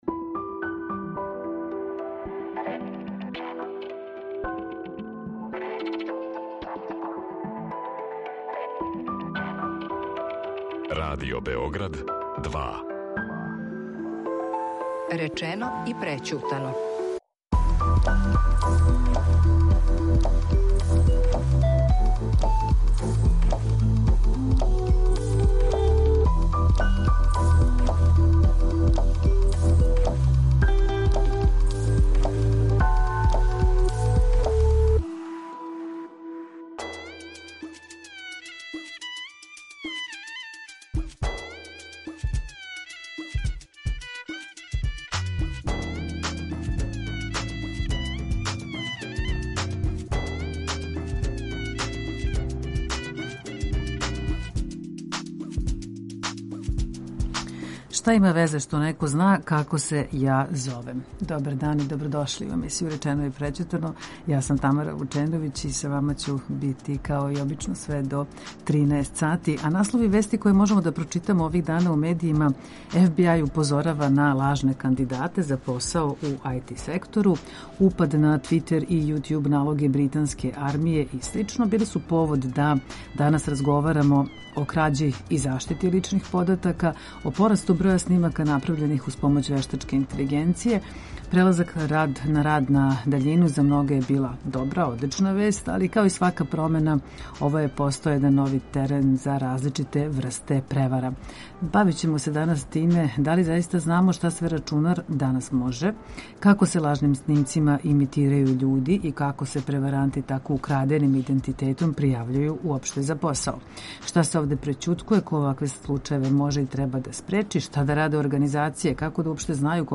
Са нама уживо: